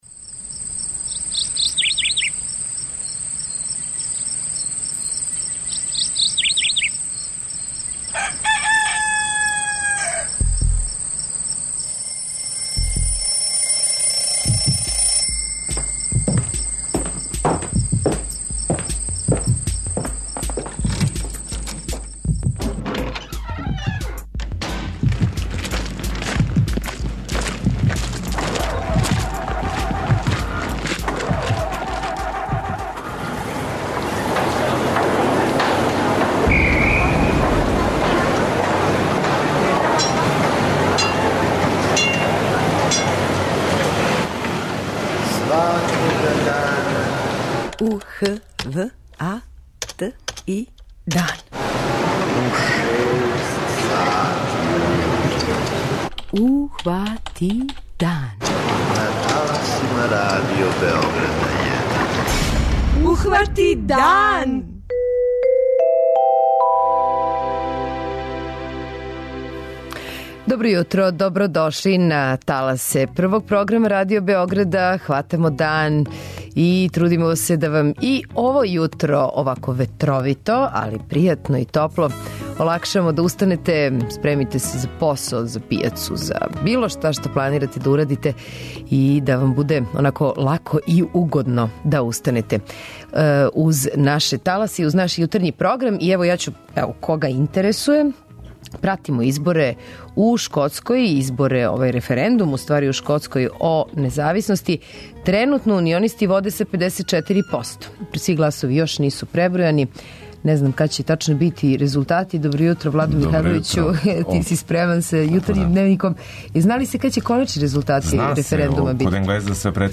преузми : 85.92 MB Ухвати дан Autor: Група аутора Јутарњи програм Радио Београда 1!